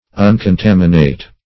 uncontaminate - definition of uncontaminate - synonyms, pronunciation, spelling from Free Dictionary
uncontaminate.mp3